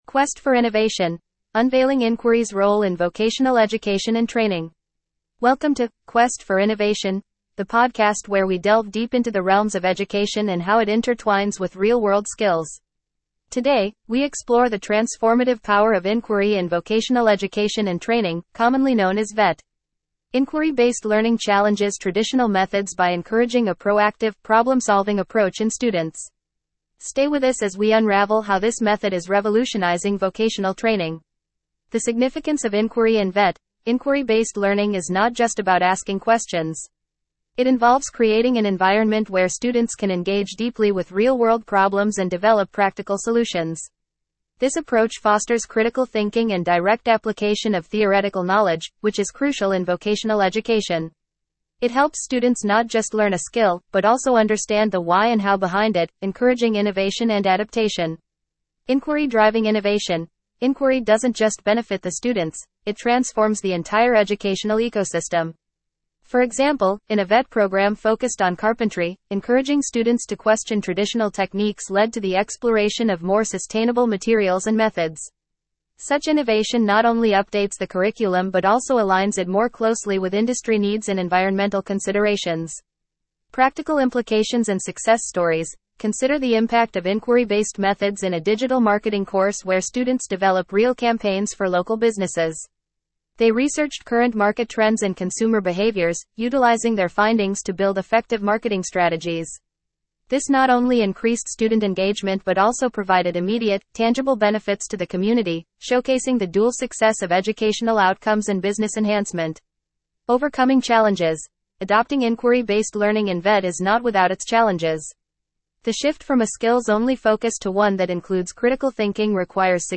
Video – Multimedia content to be watched After watching the relevant VIDEO, we suggest that you listen to the following podcast featuring experts discussing the role of inquiry in VET innovation. In this podcast about the role of inquiry in vocational educational training innovation, you can understand how inquiry-based methods can drive improvements and adaptations in vocational education.